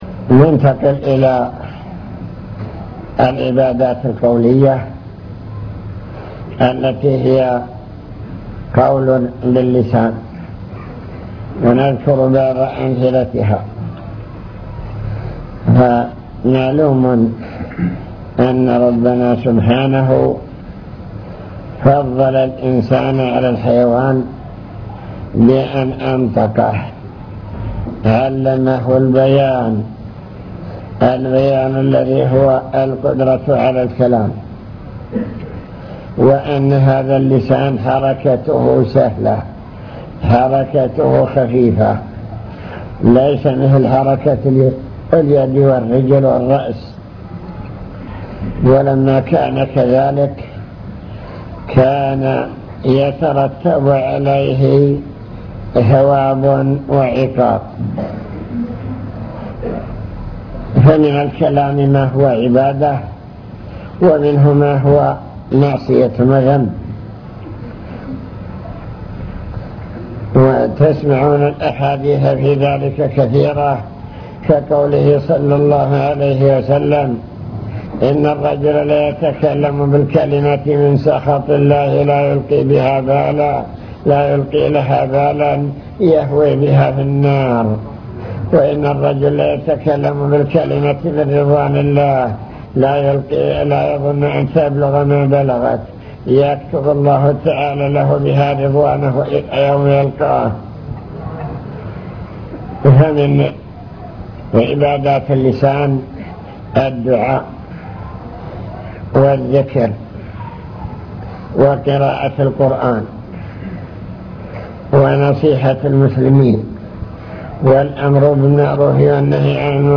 المكتبة الصوتية  تسجيلات - محاضرات ودروس  نوافل العبادات وأنواعها العبادات القولية